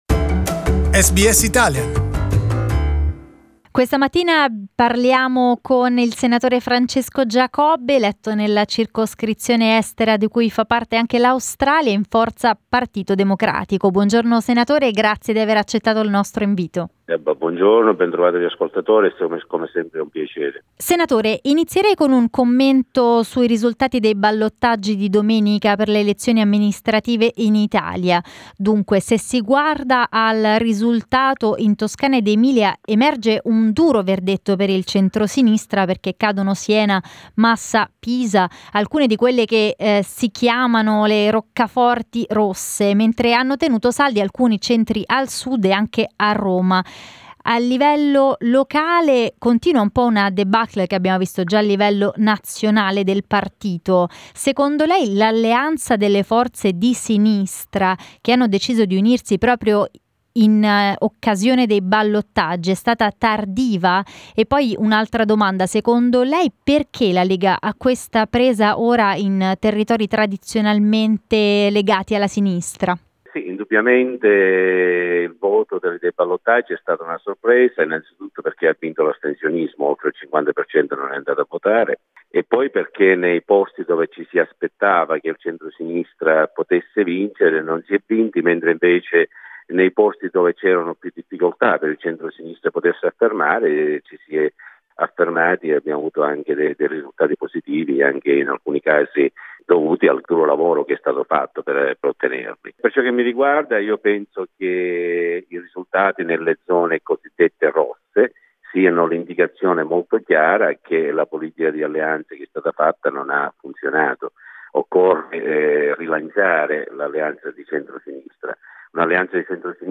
A conversation with Senator Francesco Giacobbe (PD) elected by the Italians in Australia.